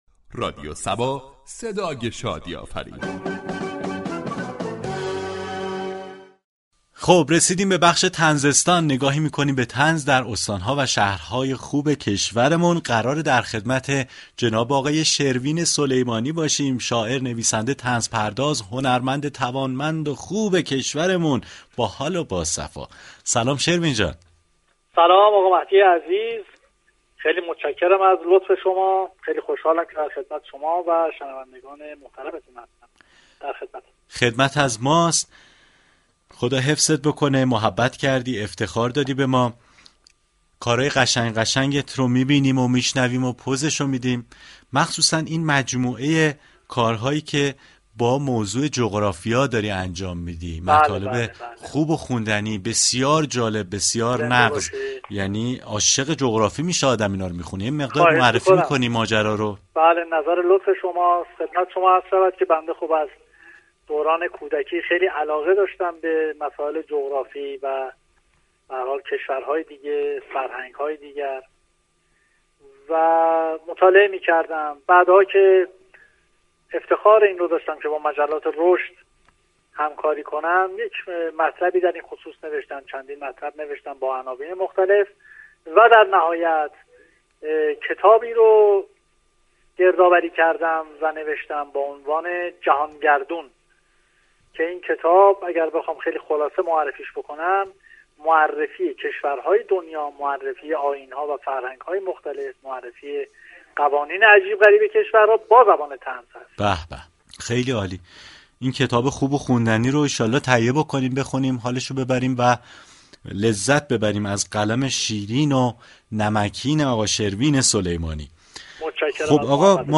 گفتگو با